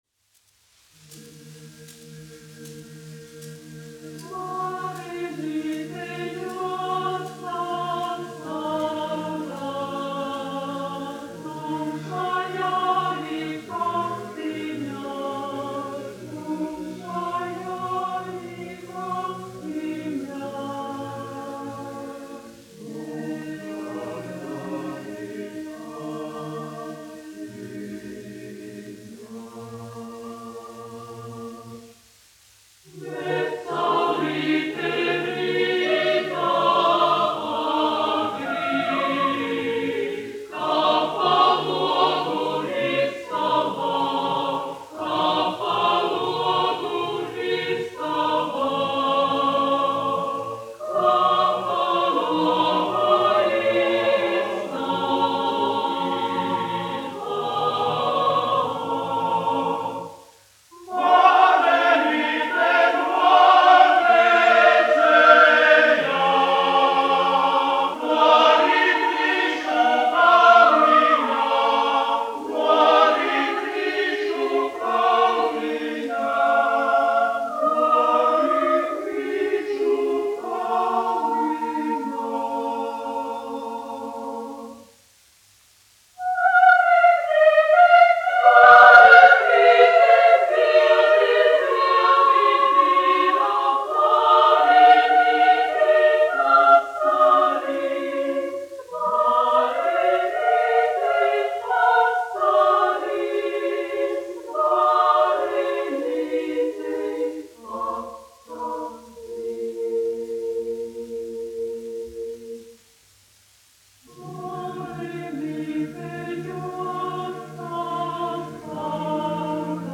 Edmunds Goldšteins, 1927-2008, aranžētājs
Latvijas Radio Teodora Kalniņa koris, izpildītājs
Kalniņš, Teodors, 1890-1962, diriģents
1 skpl. : analogs, 78 apgr/min, mono ; 25 cm
Latviešu tautasdziesmas
Latvijas vēsturiskie šellaka skaņuplašu ieraksti (Kolekcija)